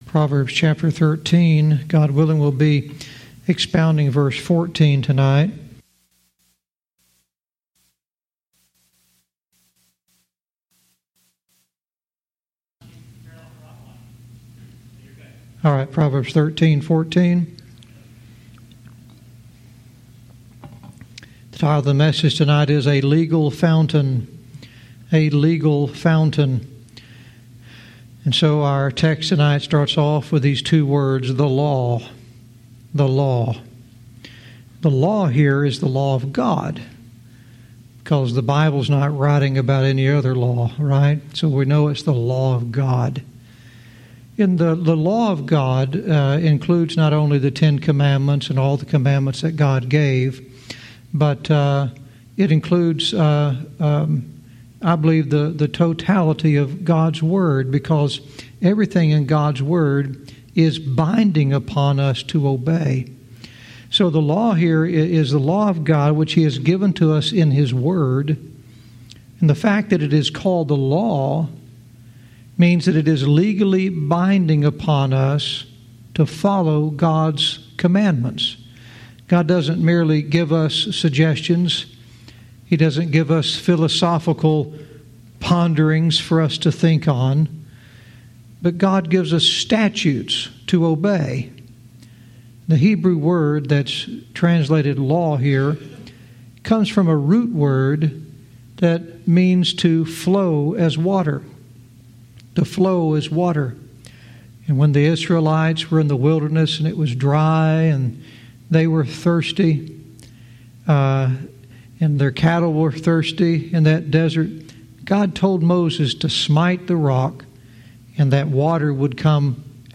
Verse by verse teaching - Proverbs 13:14 " A Legal Fountain"